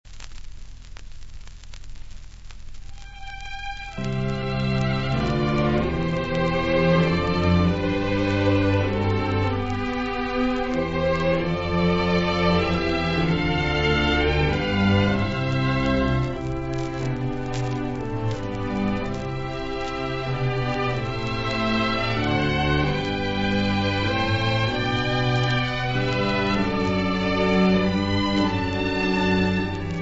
• Orchestra dell'Angelicum [interprete]
• registrazione sonora di musica